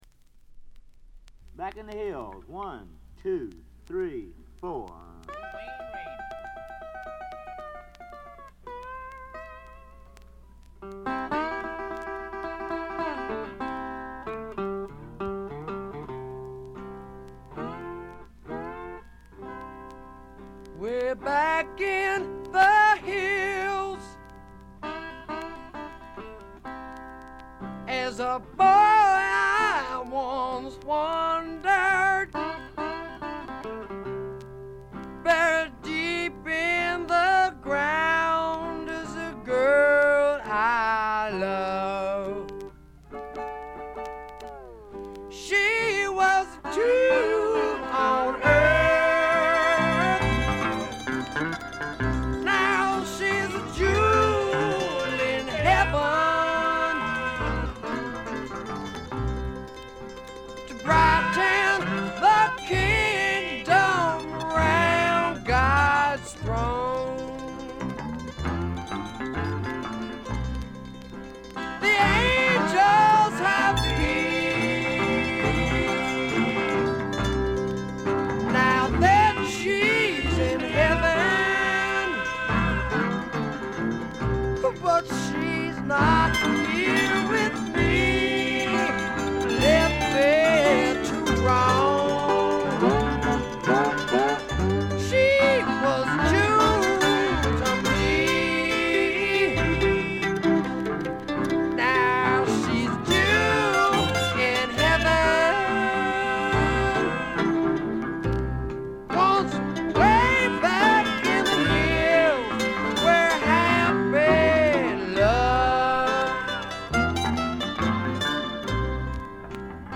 軽度のバックグラウンドノイズ、ところどころでチリプチ。
最小限の編成が織り成す、ねばつくような蒸し暑いサウンド。
試聴曲は現品からの取り込み音源です。